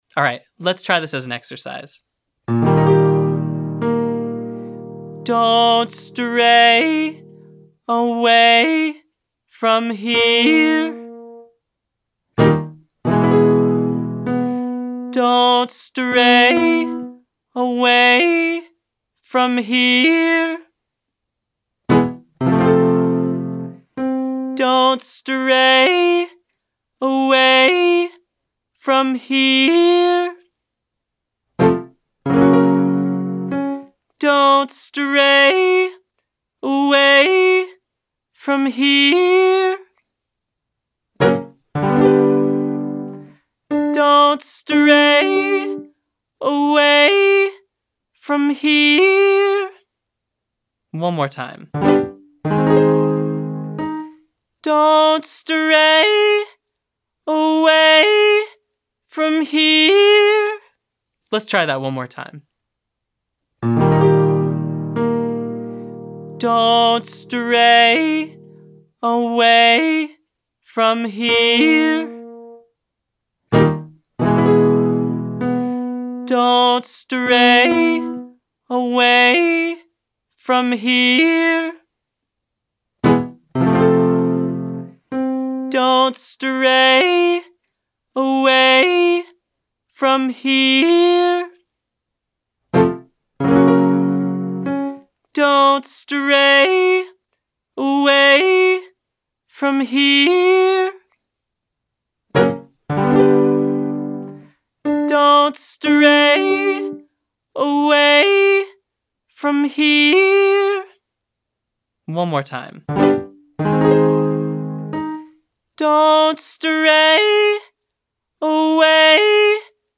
Intentional Cracking